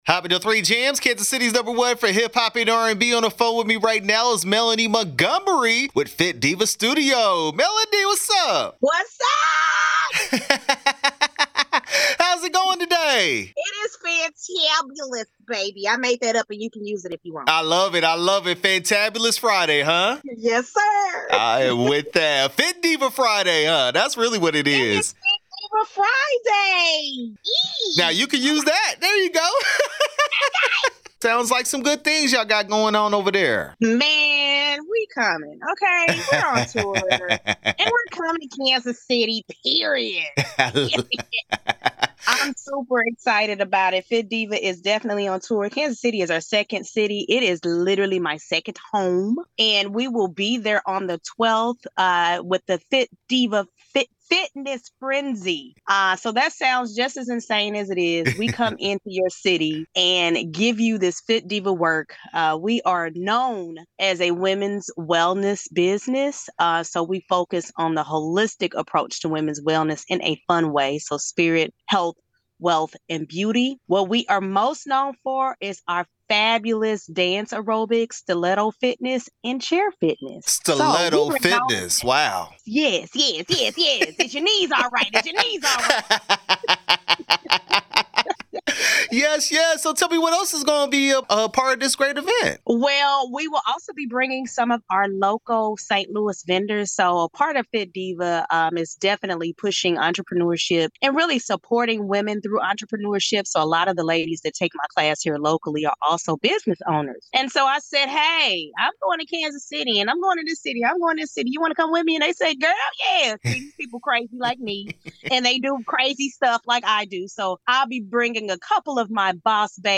Fit Diva Studio Fitness Frenzy interview 8/4/23